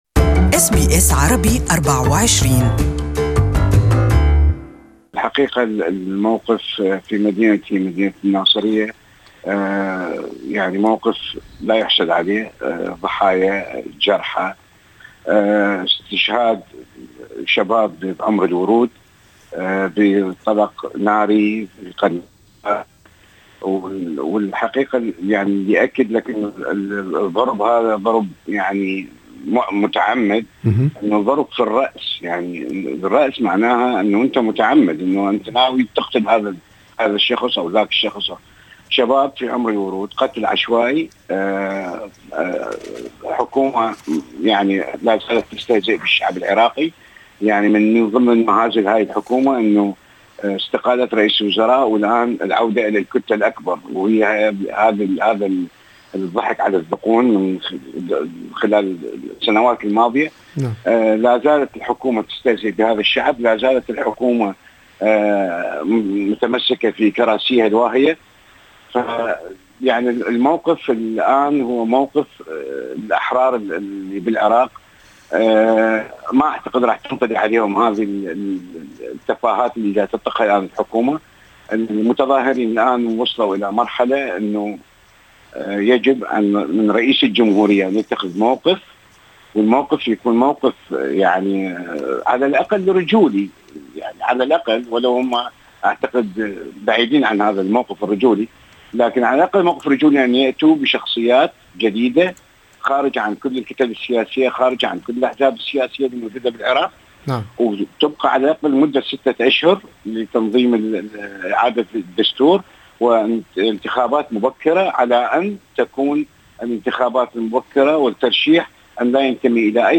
وهو يغالب دموعه